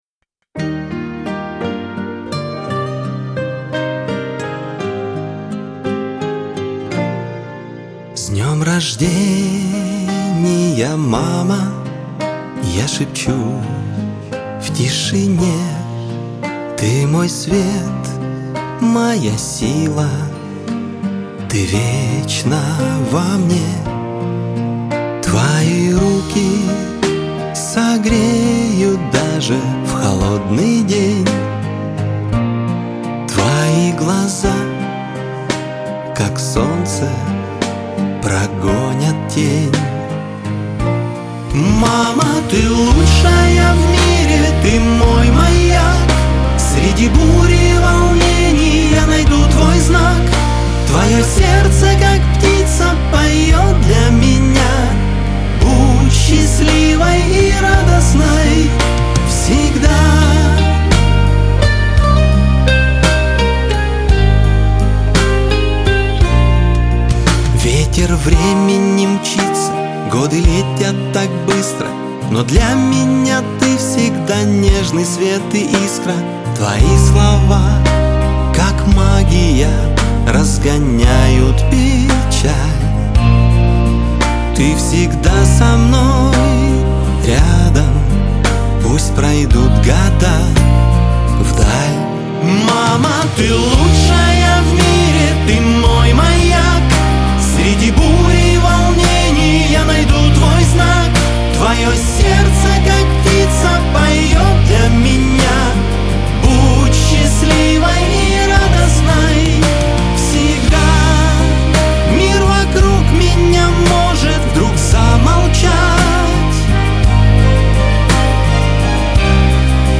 Лирика
эстрада